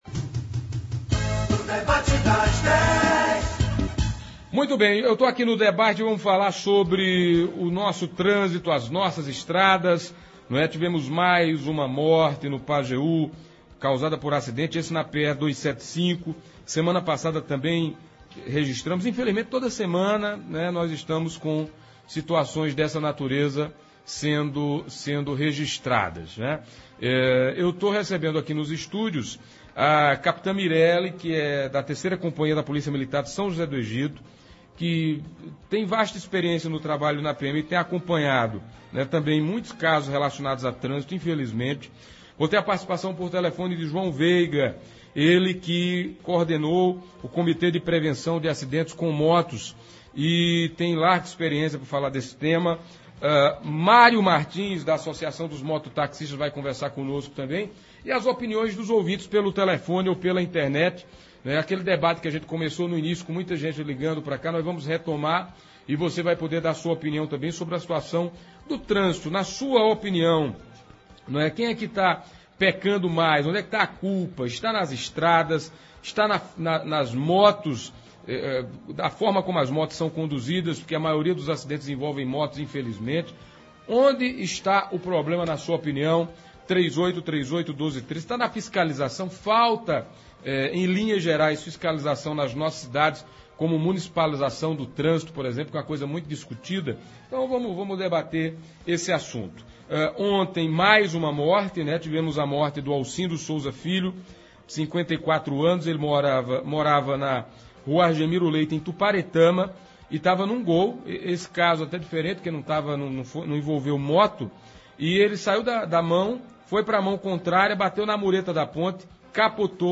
nos estúdios da Pajeú
Por telefone